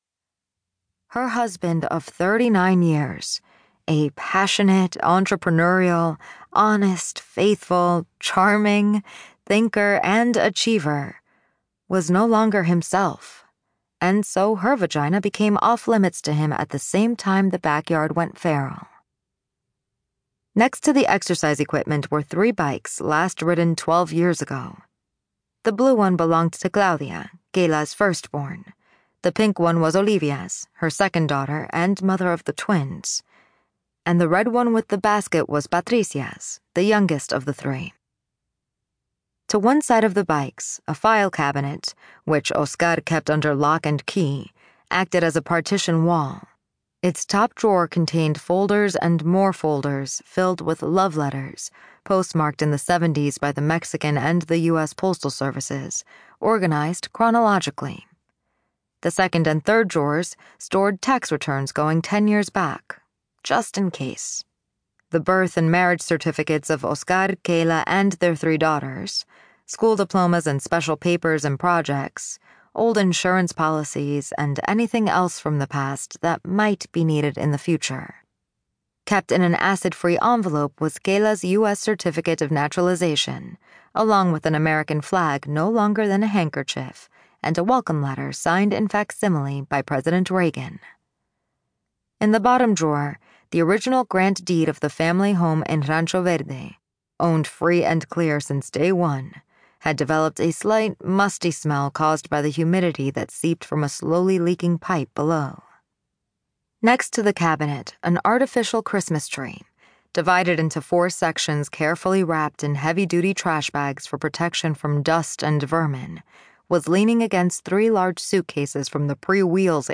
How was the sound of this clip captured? A Macmillan Audio production from Flatiron Books